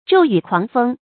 骤雨狂风 zhòu yǔ kuáng fēng
骤雨狂风发音
成语注音 ㄓㄡˋ ㄧㄩˇ ㄎㄨㄤˊ ㄈㄥ